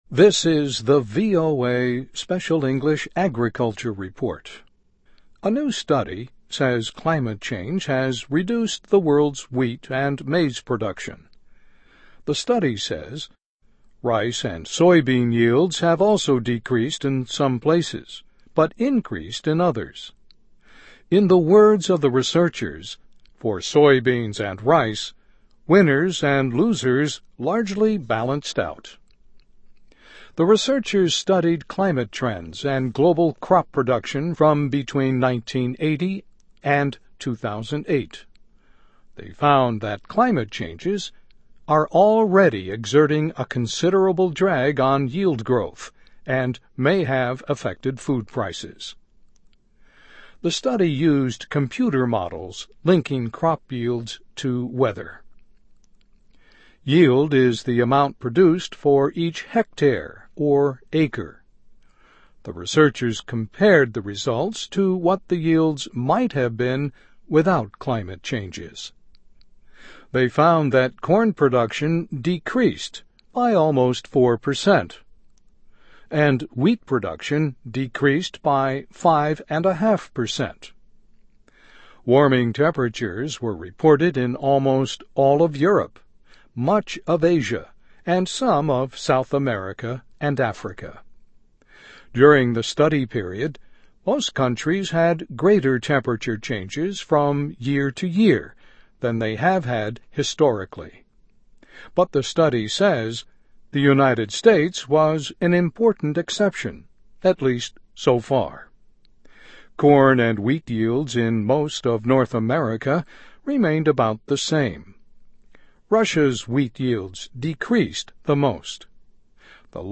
慢速英语:Study Links Climate Change to Changes in Crop Yields